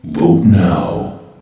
vote_now.ogg